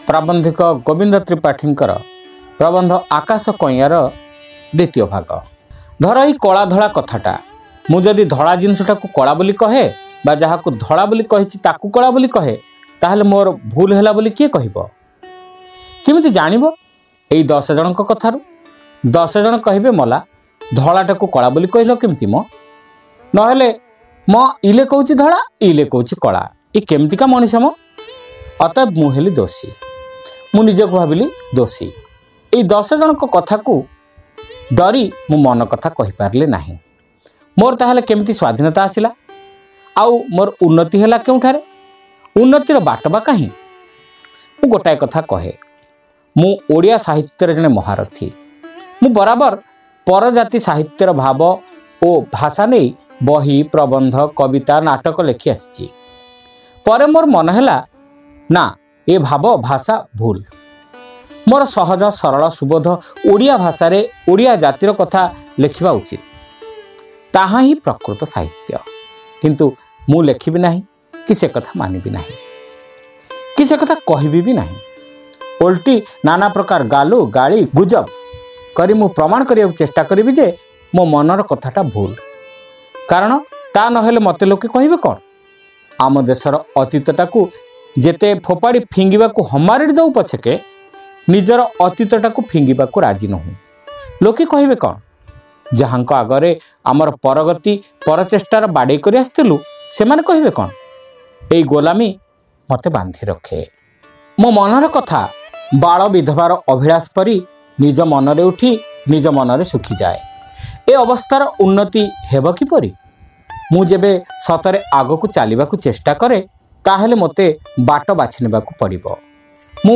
Audio Story : Akasha Kainyan (Part-2)